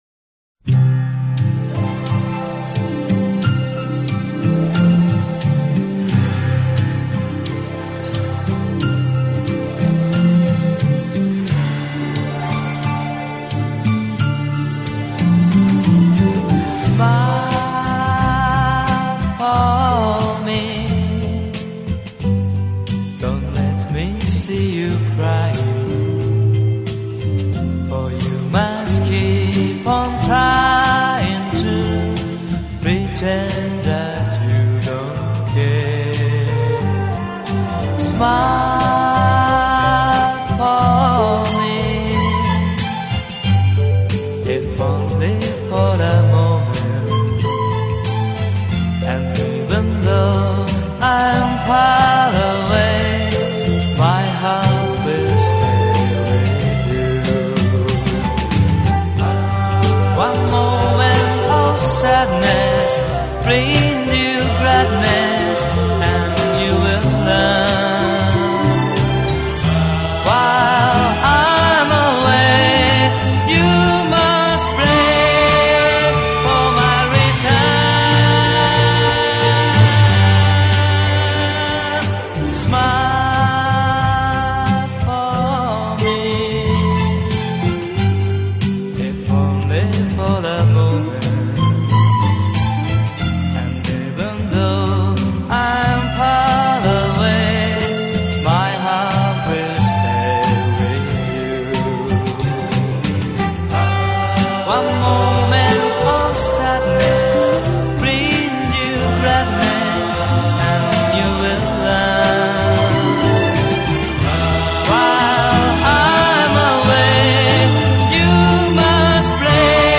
bass guitar
rhythm guitar
lead guitar and vocal
drums